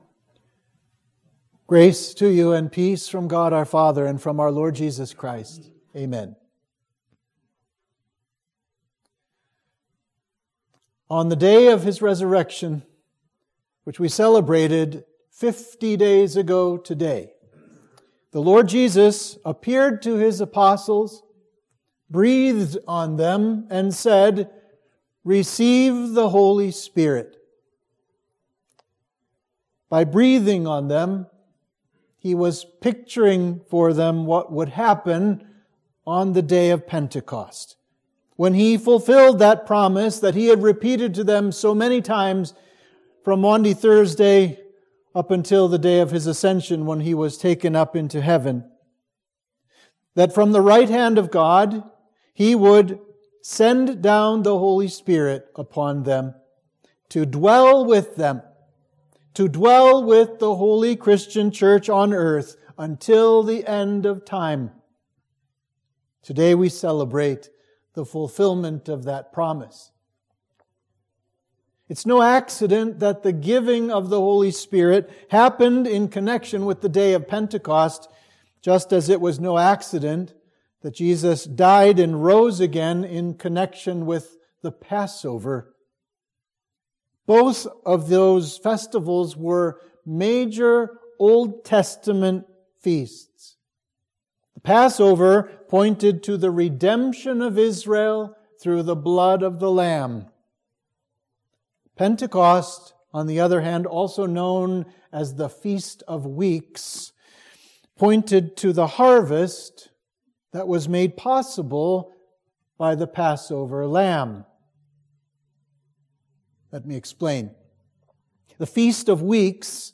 Sermon for the Day of Pentecost